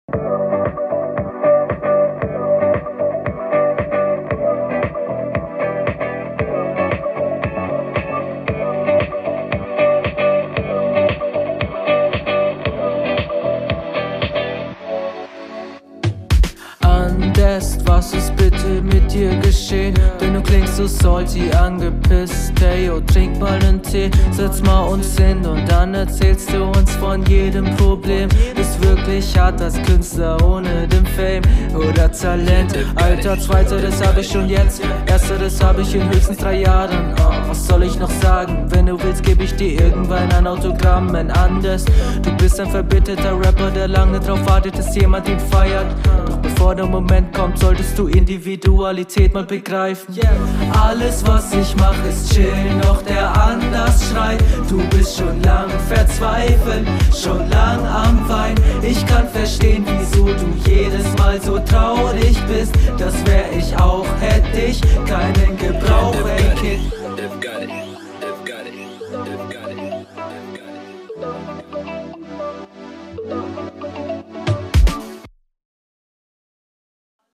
Oh yeah gute Laune Beat, lets go. Der einstieg kling schief oder einfach ungeil kp.